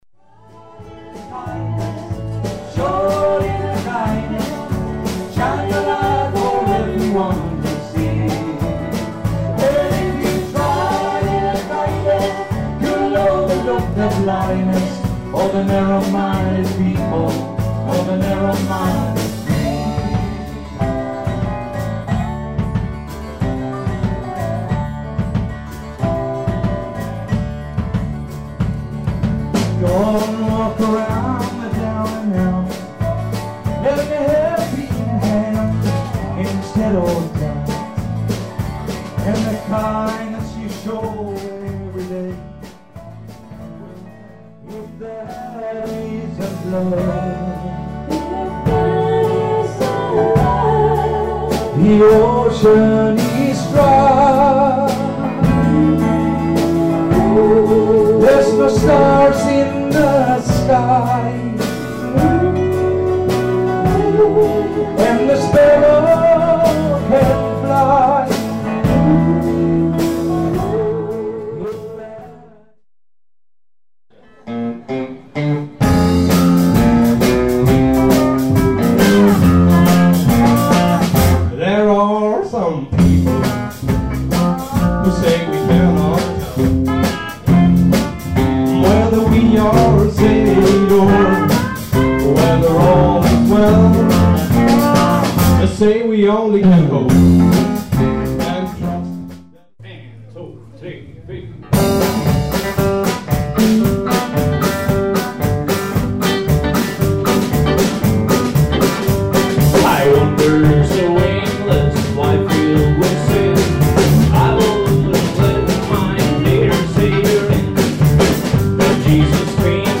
Countrygruppen The Carburators med medlemmar från Lugnås med omnejd, såg till att det blev bra drag i bygdegården i lördags kväll den 18 okt 2008.
Stundtals var tempot högt och pupliken var med och höll takten genom handklappningar.
sång o gitarr
trummor
dobroe, gitarr